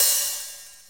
nice open2.wav